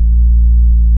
Bass (2).wav